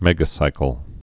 (mĕgə-sīkəl)